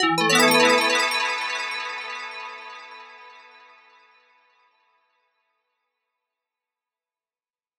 Echoes_E_02.wav